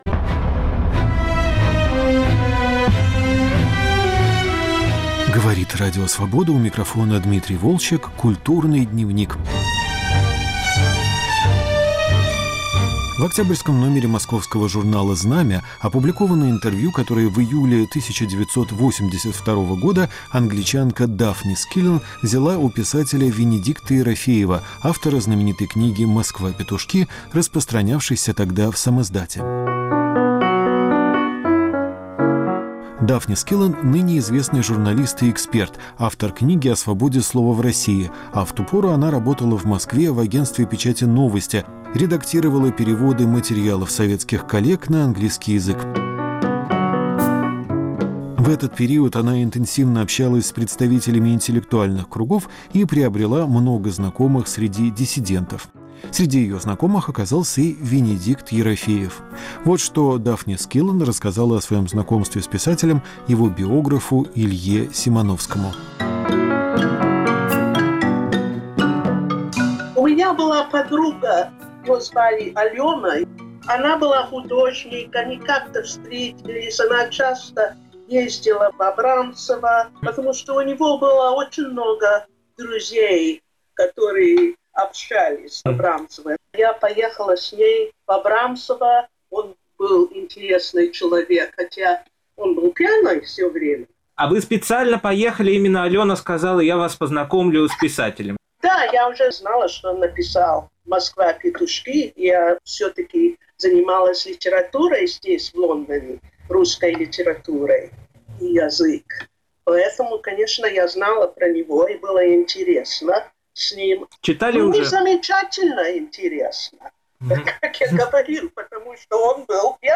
Забытое интервью Венедикта Ерофеева